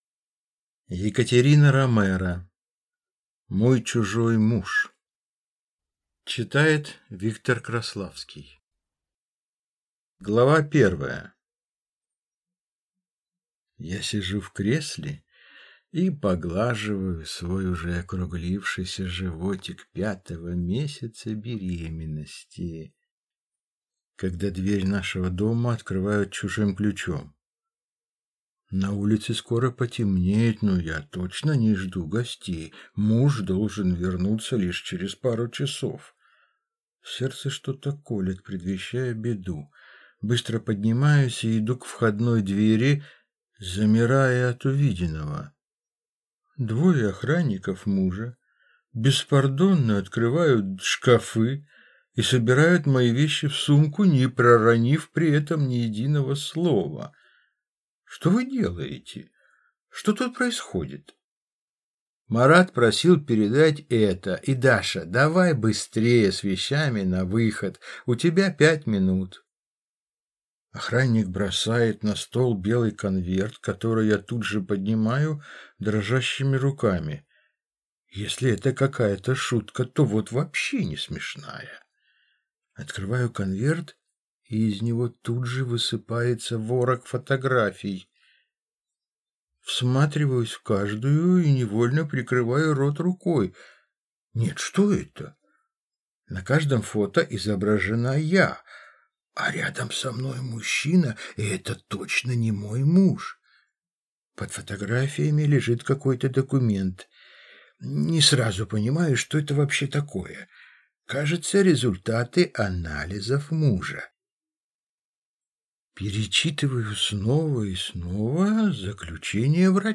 Аудиокнига «Светлая личность». Автор - Илья Ильф.